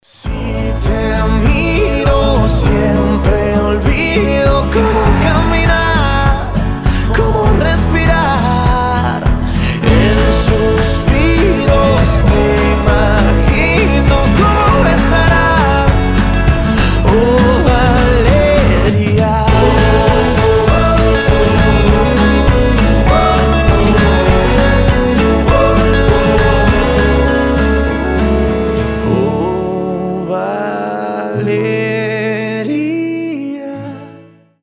• Качество: 320, Stereo
милые
летние
теплые
приятный мужской голос
Latin Pop